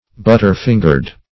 Butter-fingered \But"ter-fin`gered\
butter-fingered.mp3